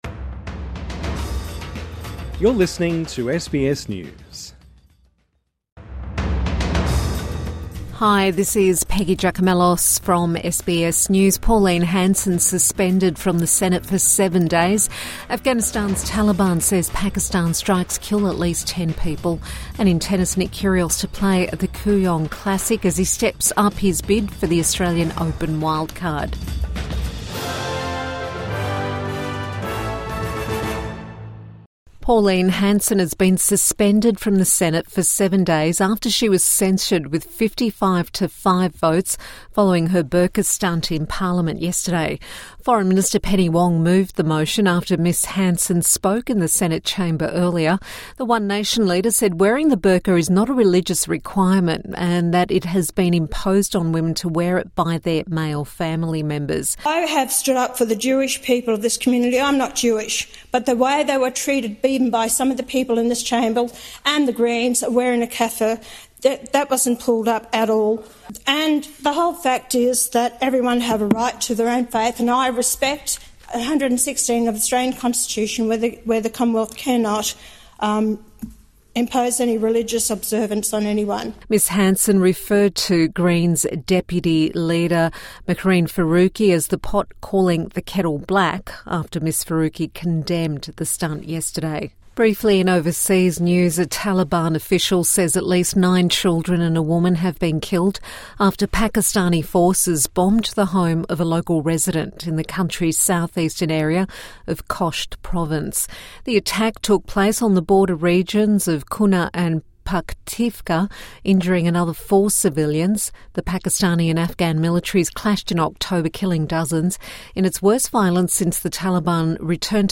Pauline Hanson suspended from the Senate for seven days | Evening News Bulletin 25 November 2025